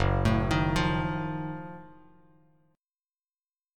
F#mM13 chord